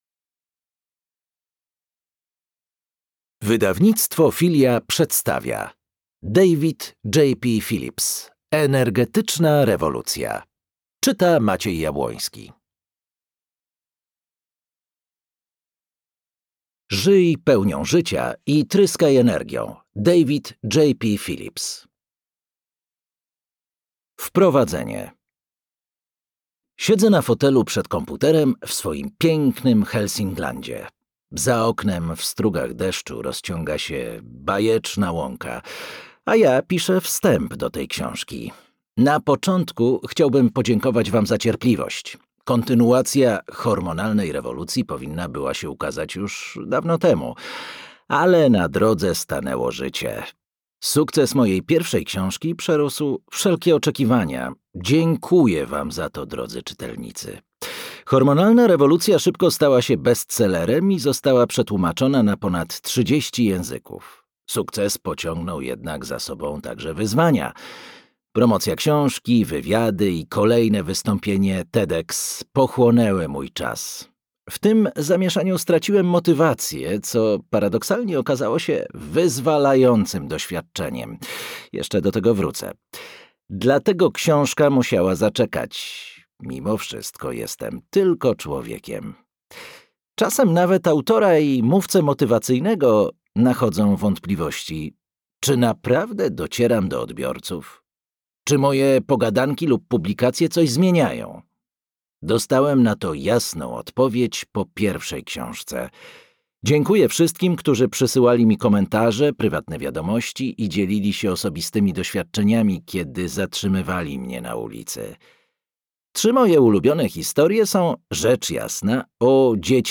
Energetyczna rewolucja - David JP Phillips - audiobook